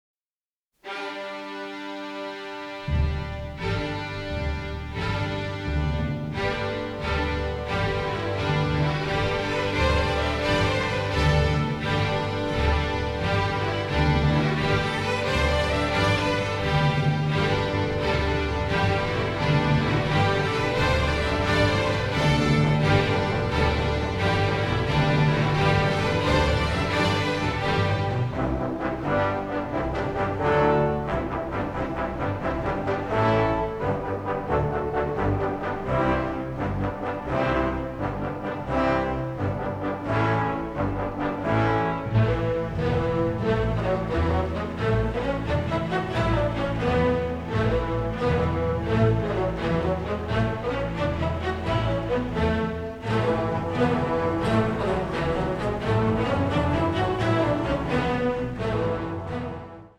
a sensitive, dramatic, delicate score with an Irish flavor